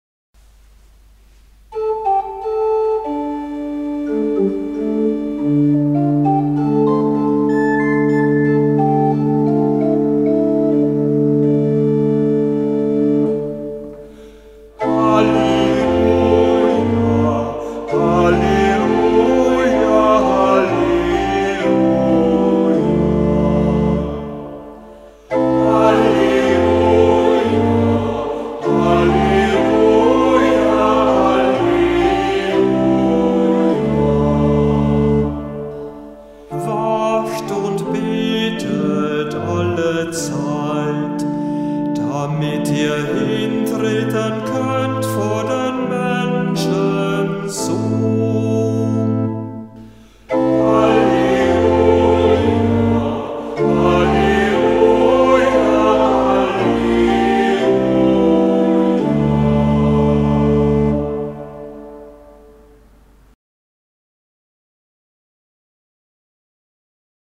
Ruf vor dem Evangelium - November 2024
Kantor der Verse